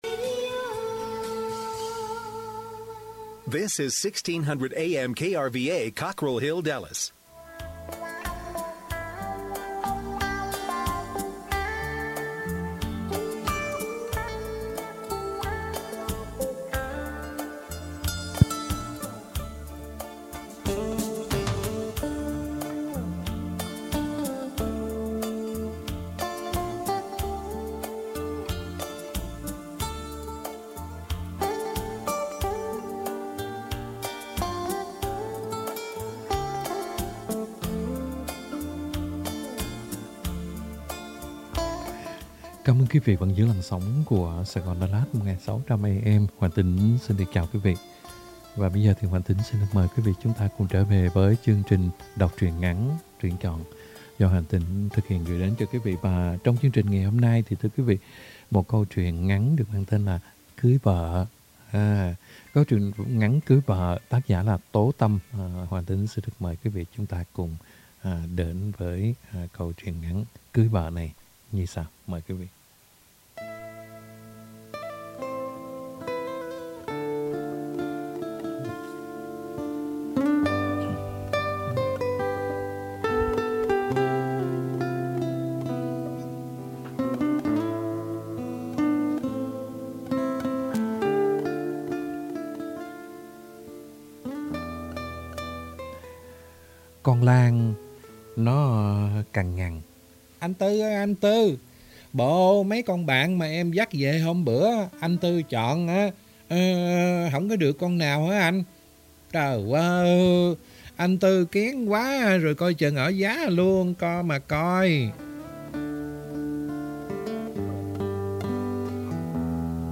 Đọc Truyện Ngắn = Cưới Vợ - 12/07/2021 .